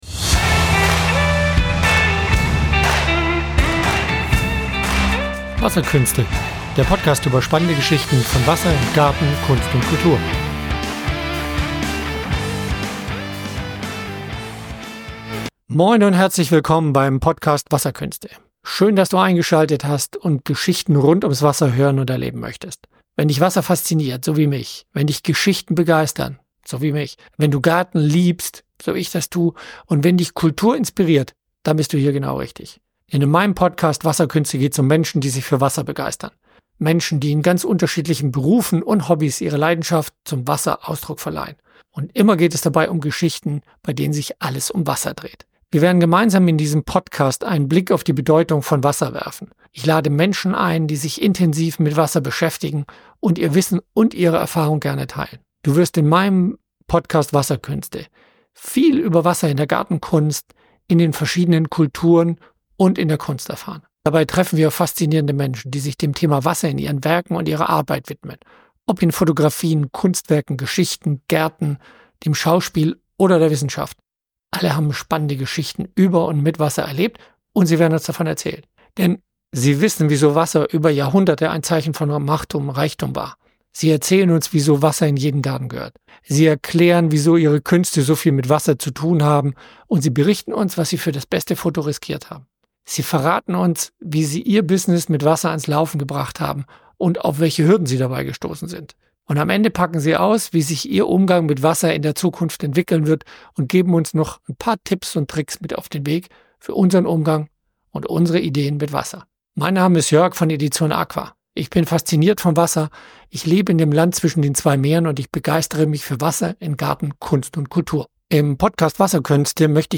Kunst, Kultur und Technologie. Interviews mit Expert:Innen,
die Tonqualität manchmal.